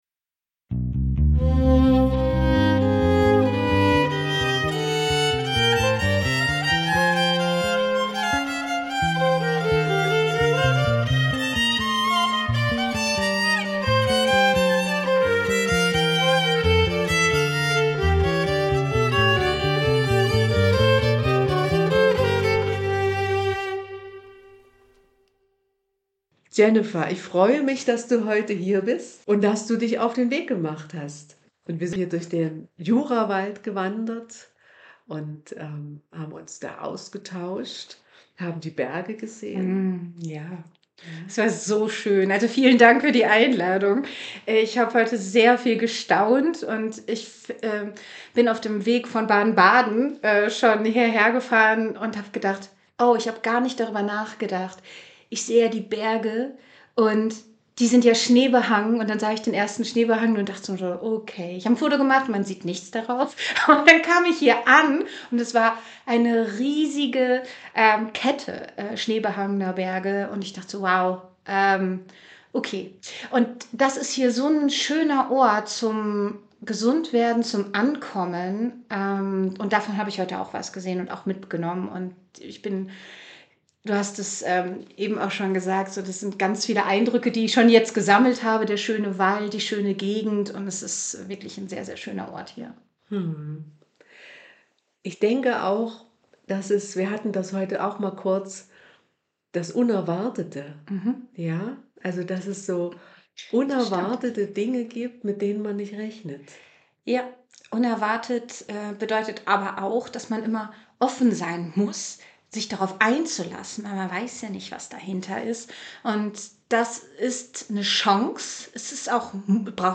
Ein spannendes Gespräch über digitale Medien und persönliche Kreativität!